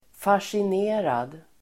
Ladda ner uttalet
fascinerad adjektiv, fascinated Uttal: [fasjin'e:rad]